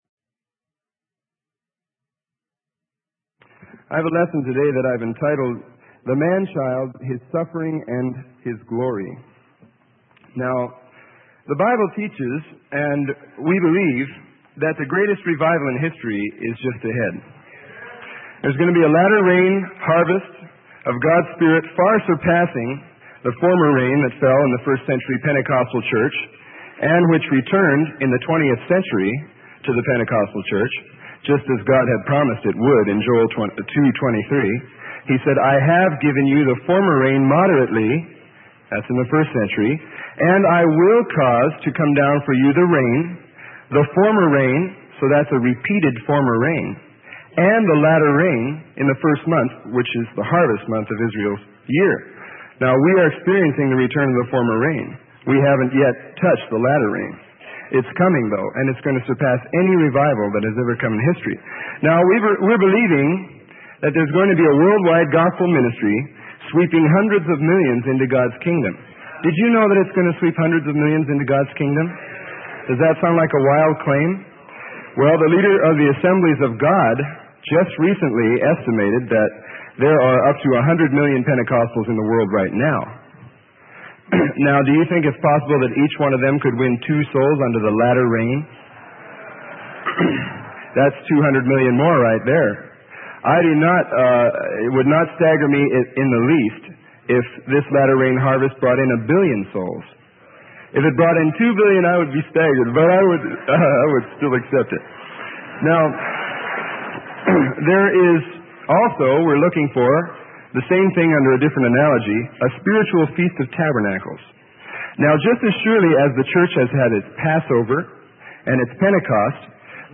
Sermon: The Manchild: His Suffering and His Glory - Camp Meeting 76 - Freely Given Online Library